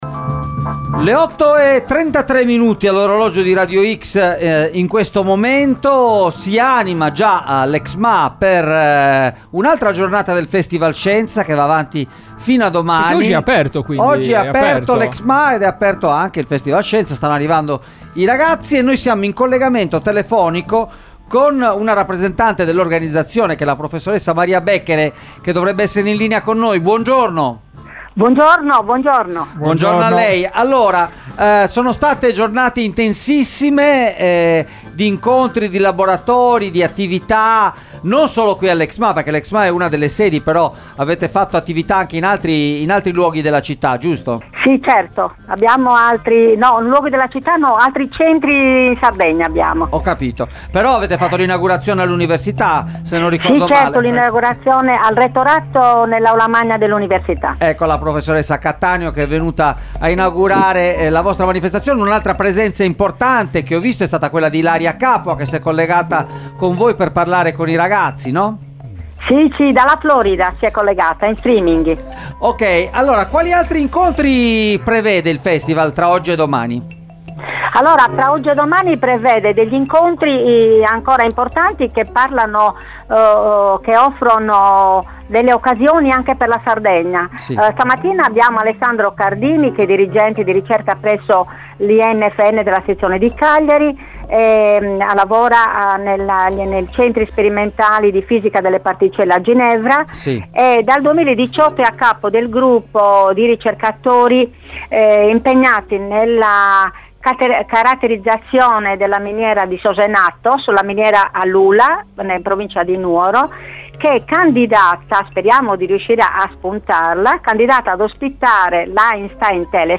Ne abbiamo parlato all’interno di Extralive mattina in collegamento telefonico